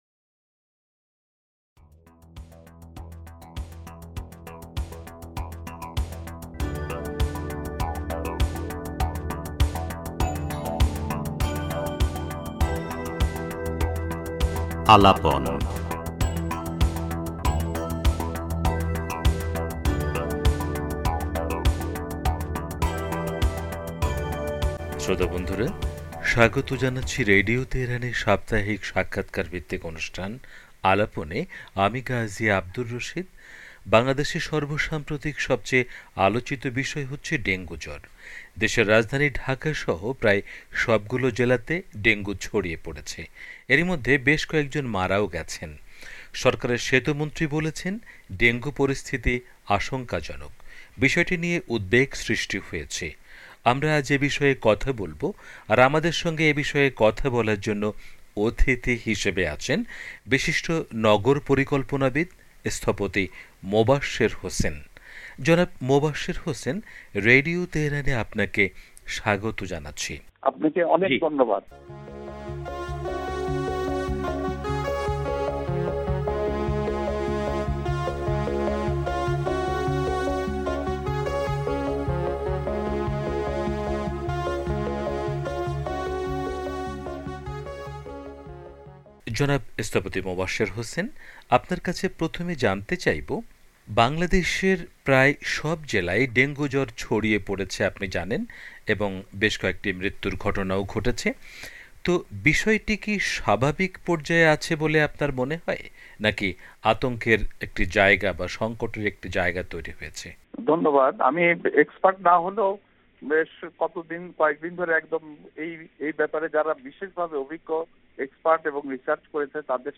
রেডিও তেহরানকে দেয়া বিশেষ সাক্ষাৎকারে একথা বলেছেন স্থপতি